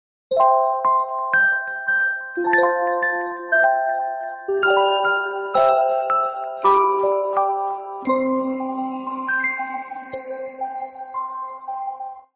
• Качество: 128, Stereo
праздничные
Навевает праздничное настроение!